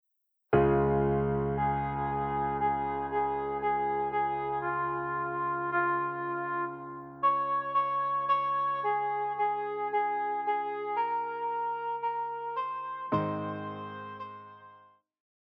Classical
Vocal - female,Vocal - male
Piano
Voice with accompaniment
This arrangement is in Ab major.